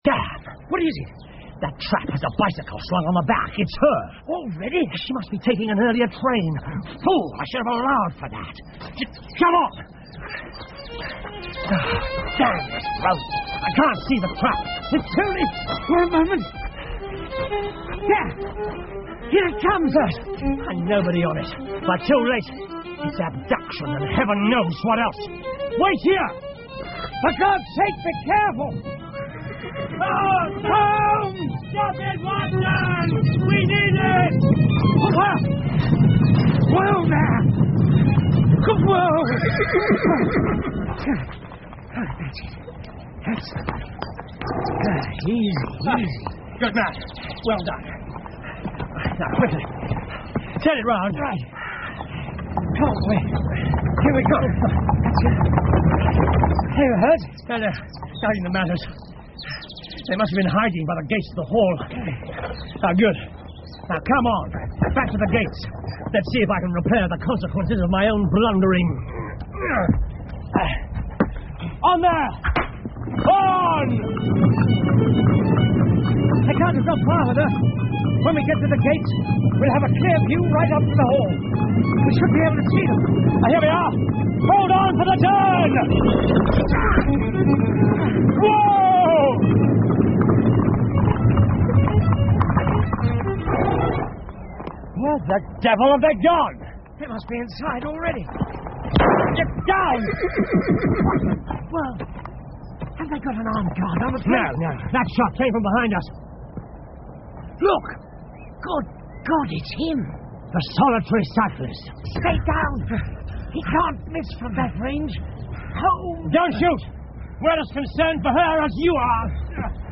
福尔摩斯广播剧 The Solitary Cyclist 7 听力文件下载—在线英语听力室